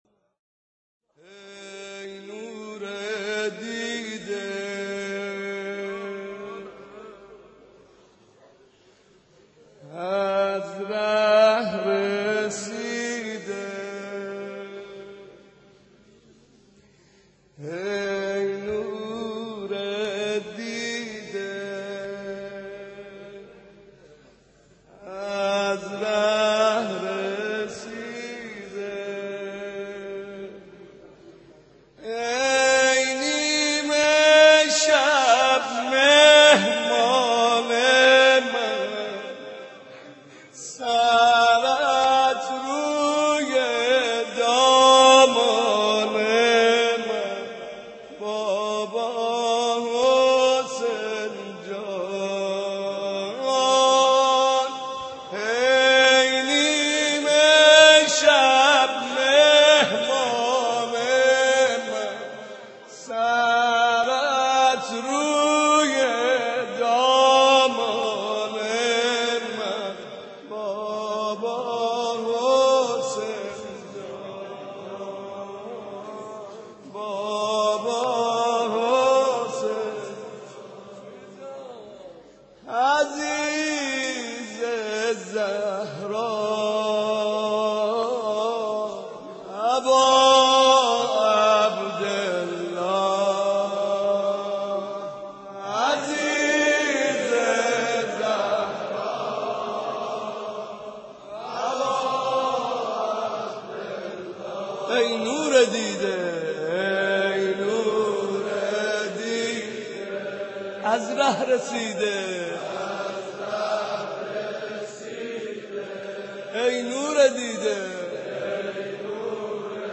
محرم شب سوم
مداح اهل بیت